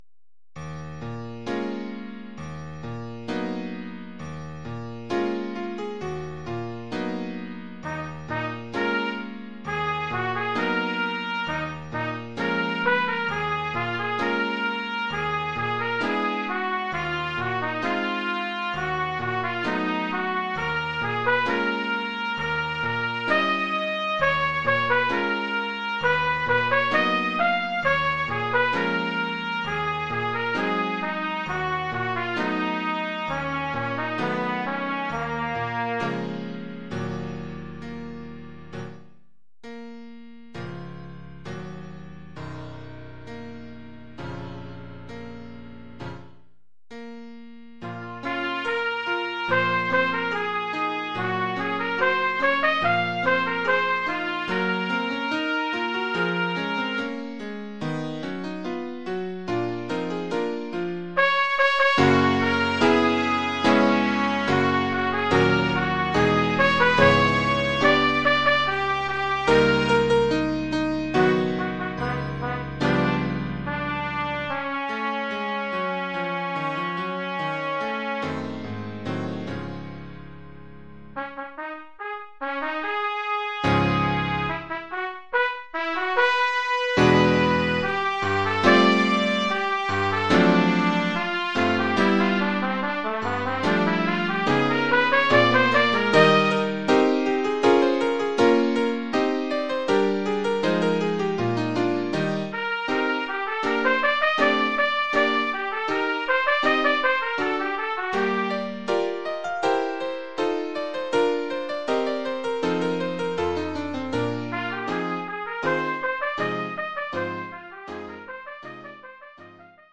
Oeuvre pour trompette ou cornet
ou bugle et piano..